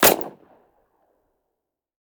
sounds / weapons / thompson